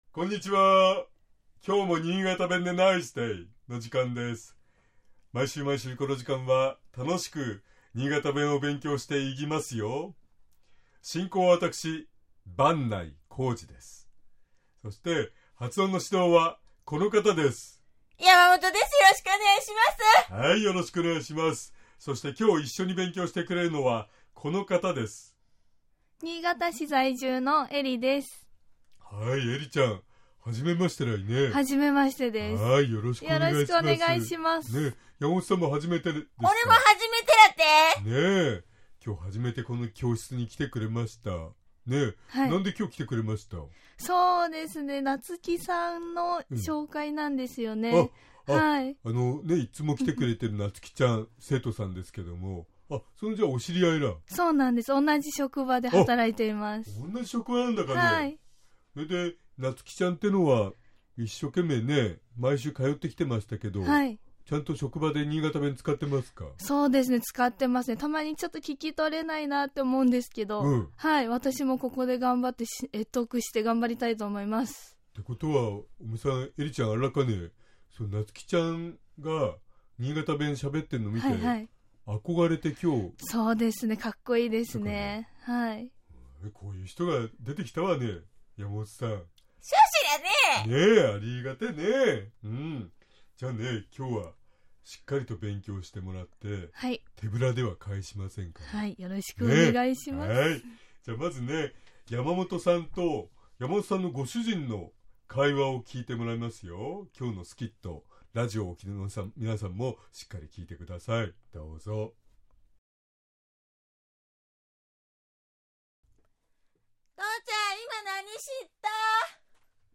（発音練習）
（スキット）
尚、このコーナーで紹介している言葉は、 主に新潟市とその周辺で使われている方言ですが、 それでも、世代や地域によって、 使い方、解釈、発音、アクセントなどに 微妙な違いがある事を御了承下さい。